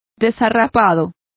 Complete with pronunciation of the translation of shabbiest.